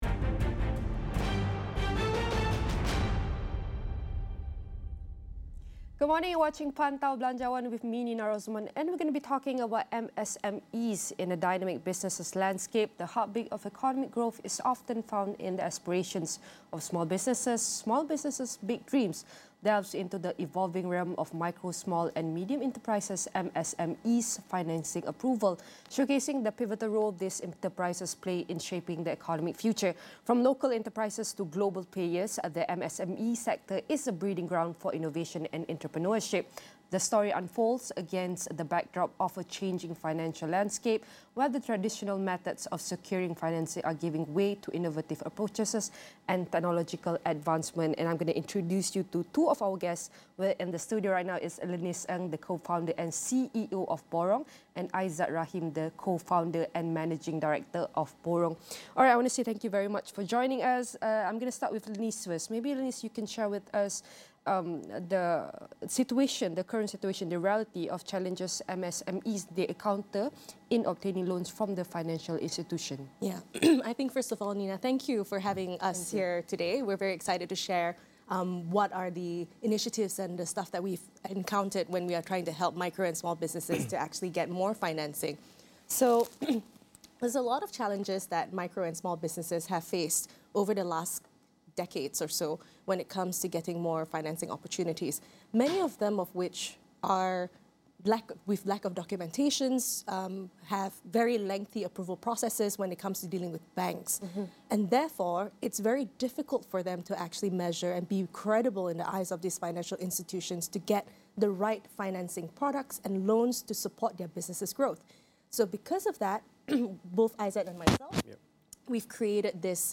MSMEs face loan challenges due to lengthy processes and collateral demands. Borong, in partnership with BSN, supports MSMEs under Belanjawan 2024, addressing hurdles and promoting financial inclusion. Full discussion